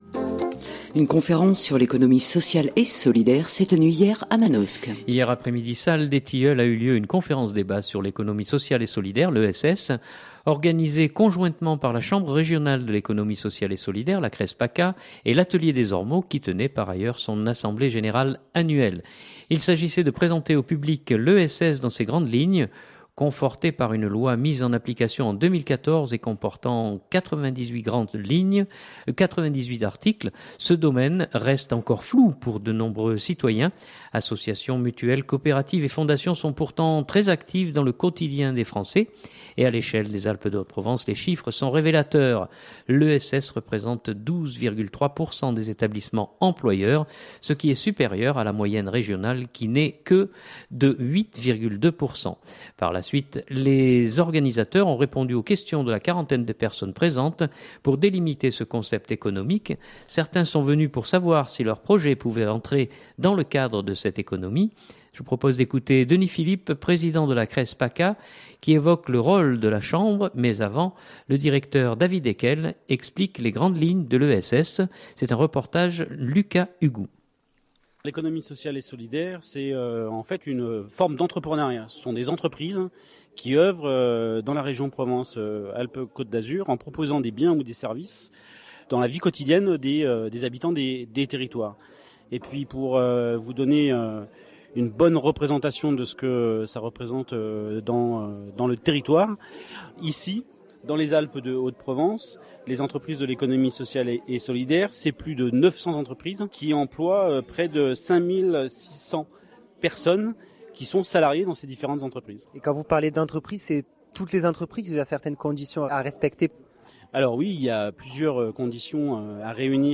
Hier après midi salle des tilleuls a eu lieu une conférence débat sur l'économie sociale et solidaire (ESS).